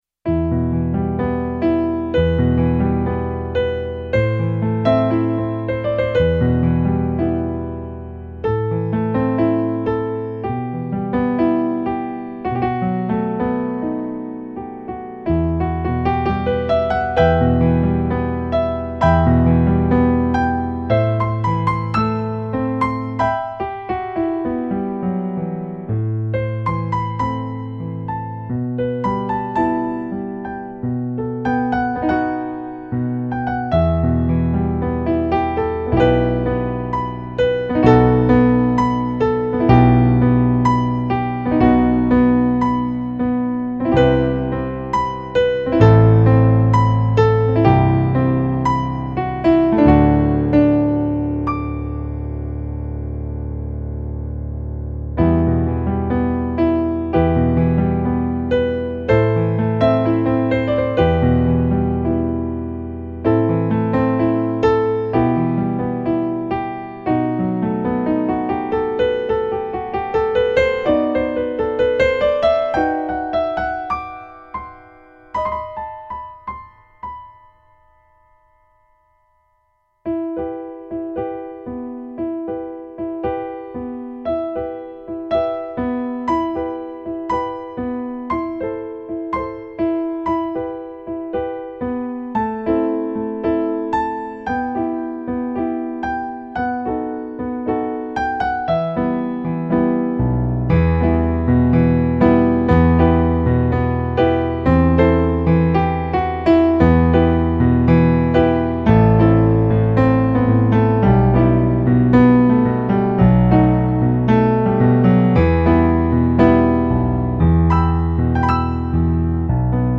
eight piano solo arrangements.  31 pages.
Latin remix